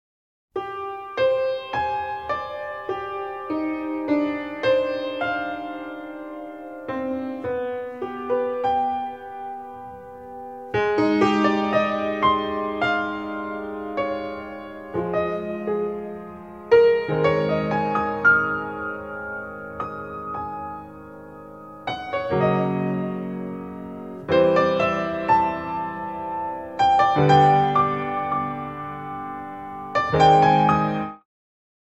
ballet
harp and piano